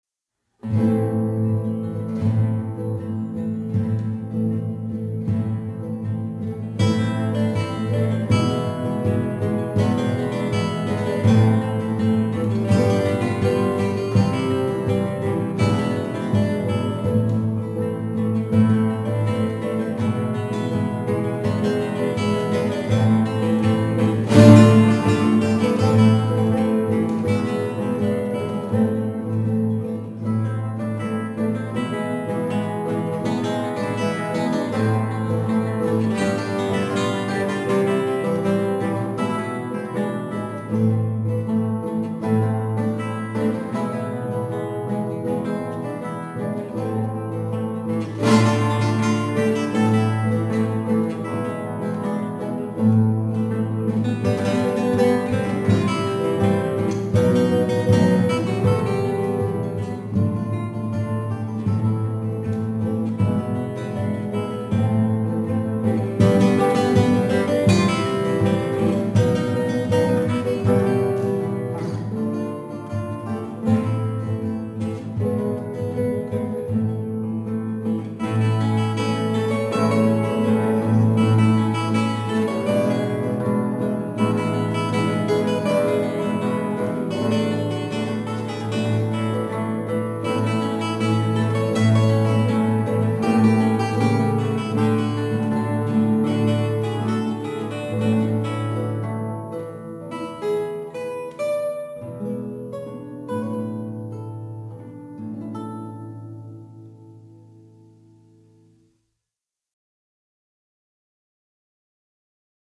Habanera 1.52